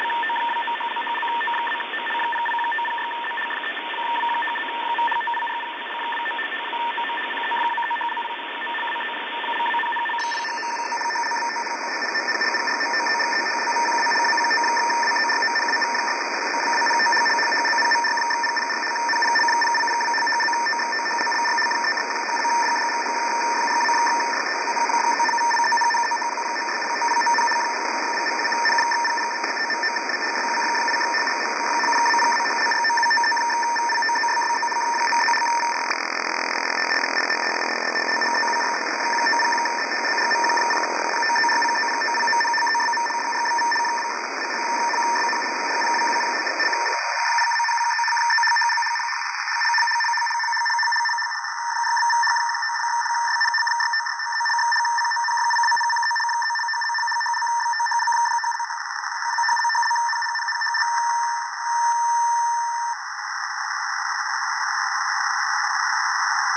Начало » Записи » Радиоcигналы на опознание и анализ
Необычный RTTY